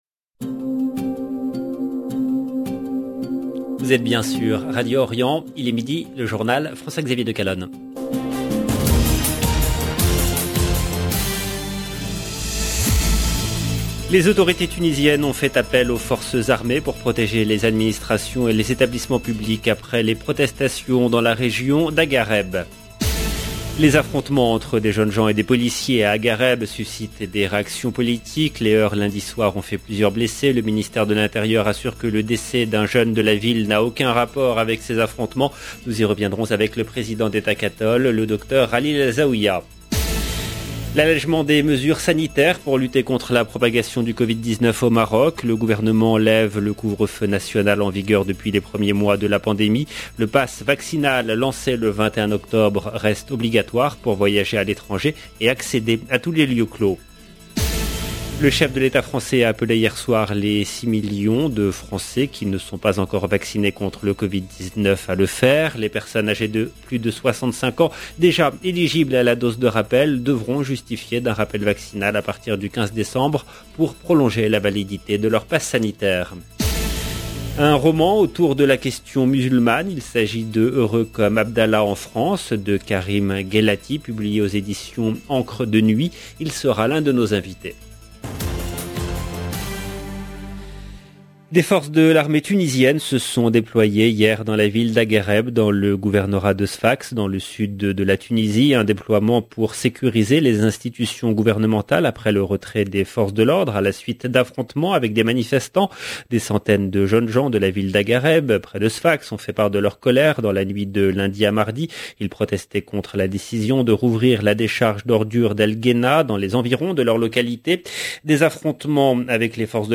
Tunisie Macron 10 novembre 2021 - 18 min 9 sec LE JOURNAL DE MIDI DU 10/11/21 LB JOURNAL EN LANGUE FRANÇAISE Les autorités tunisiennes ont fait appel aux forces armées pour protéger les administrations et les établissements publics après les protestations dans la région d’Agareb.